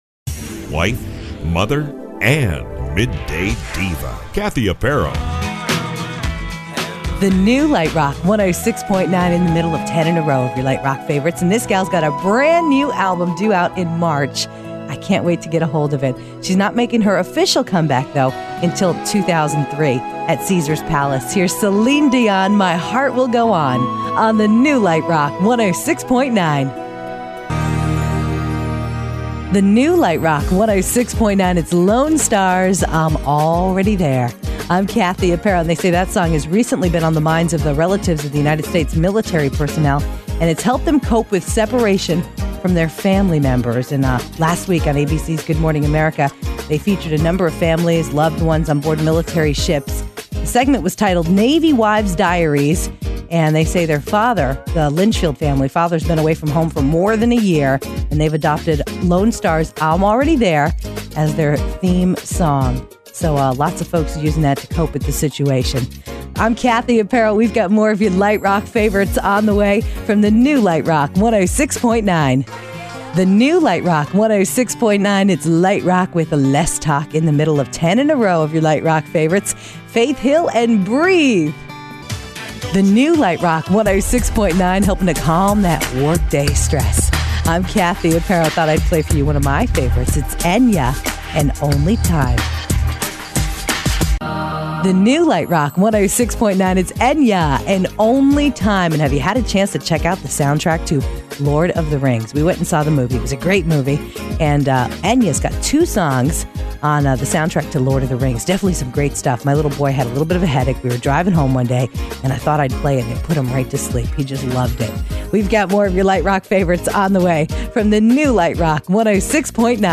Voice Tracking Demos
(Voice Tracked)
Lite Rock